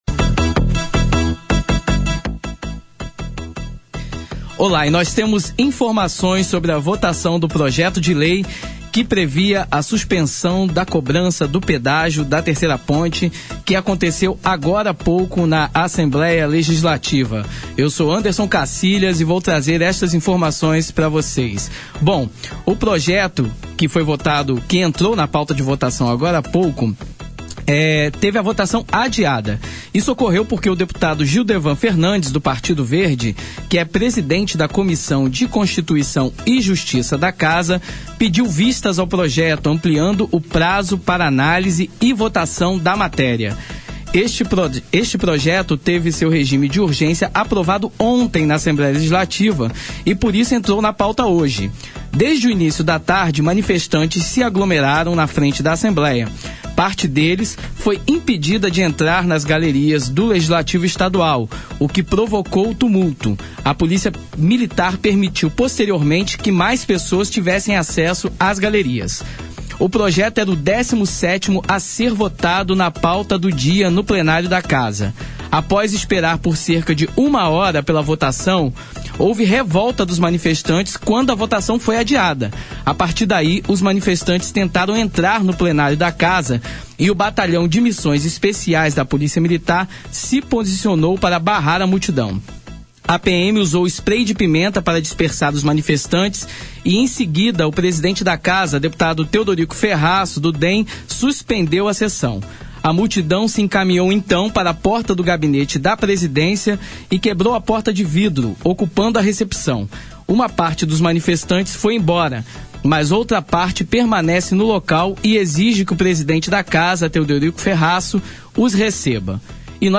Suspensão do pedágio da 3ª Ponte: 104,7 FM ouve manifestante de dentro da Assembleia | Ufes FM